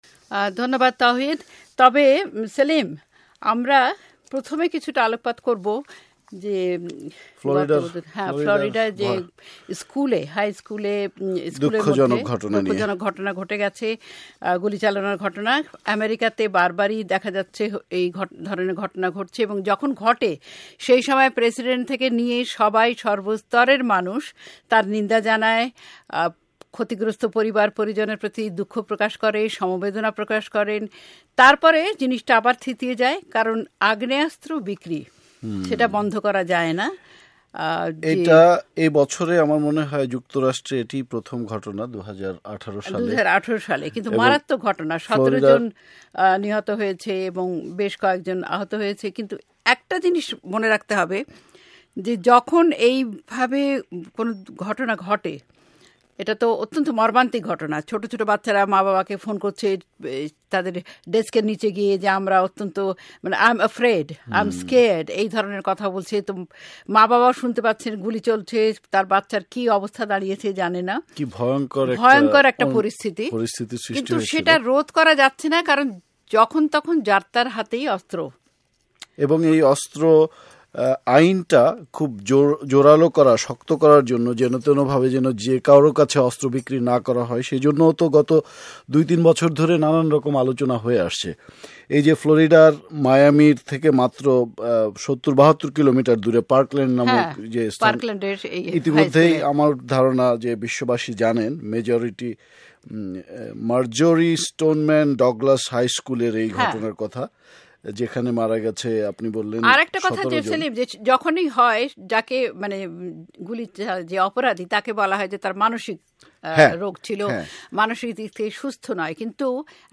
আজকের আলাপনে ঢাকার বাংলা একাডেমীর একুশের গ্রন্থমেলা ২০১৮ নিয়ে আলোচনা। ১৯৭২ সালে ঢাকা বিশ্ববিদ্যালয় সংলগ্ন বর্ধমান হাউজ প্রাঙ্গনে বটতলায় এক টুকরো চটের ওপর কলকাতা থেকে আনা ৩২টি বই সাজিয়ে যে বইমেলার গোড়াপত্তন করেন তা এখন বাংলাদেশের আণ্যতম প্রধান একটি উৎসবে পরিণত হয়েছে।